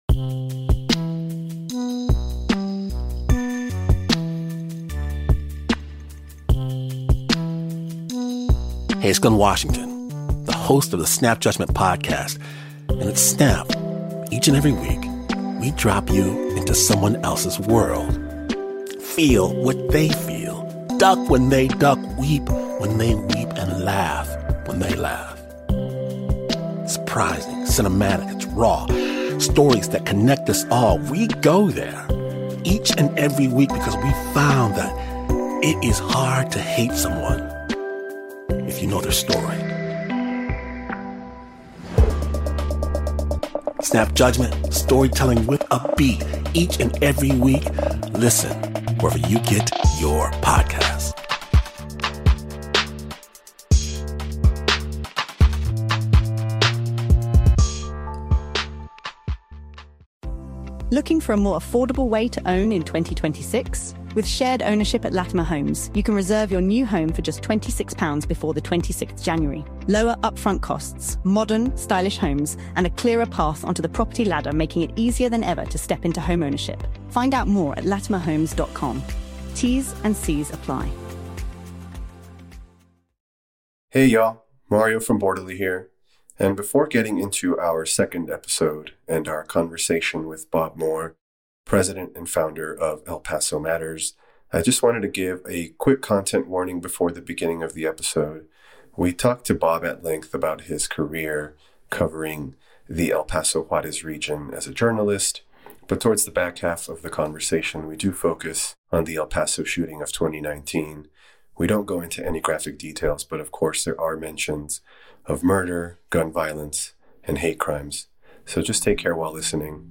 The conversation places that day within a longer arc, one shaped by language, fear, political power, and the quiet work of people who insist on documenting what really happened and why it matters.